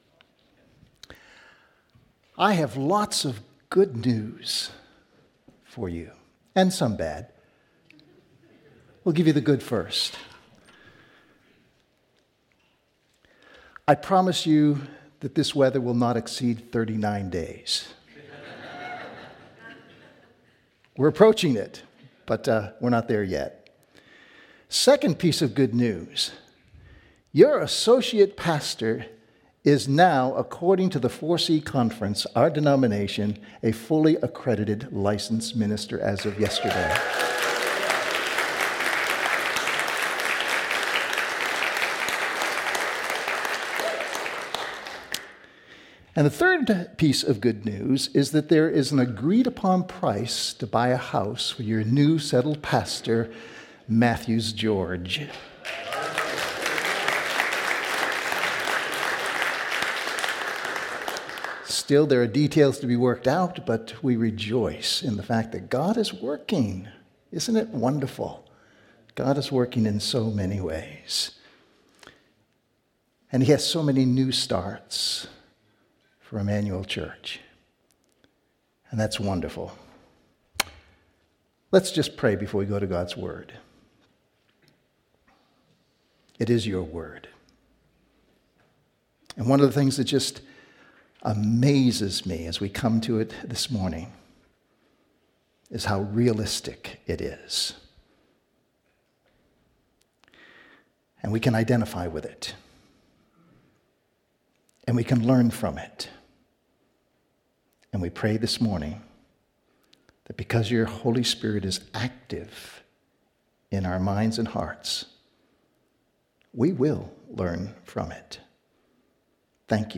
Sermons - Immanuel Church